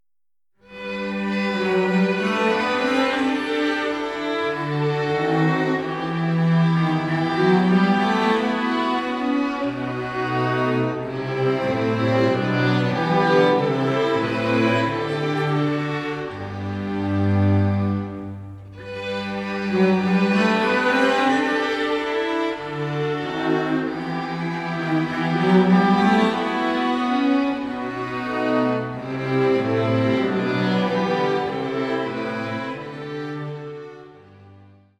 Streichensemble